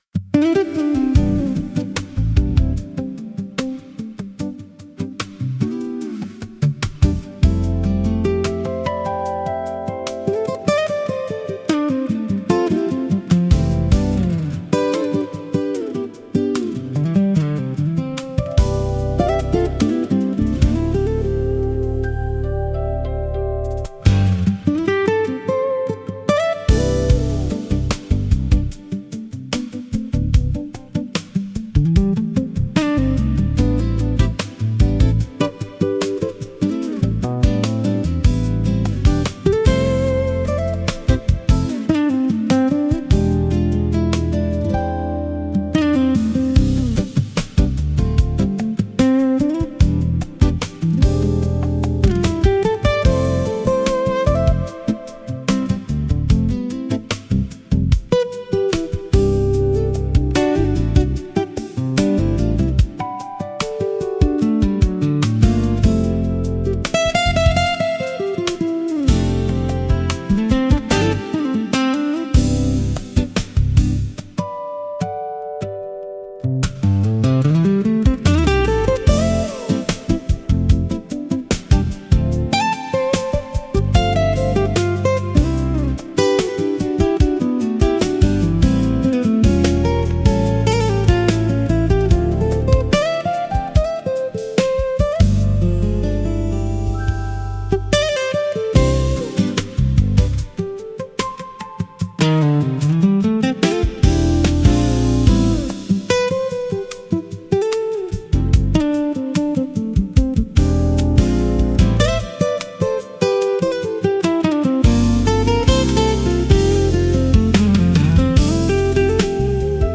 If you’re not a Clarinet apprecianado, then maybe a nice acoustic guitar sound will soothe your soul and caress your listening ears!
Penny-Moon-11-acoustic-guitar.mp3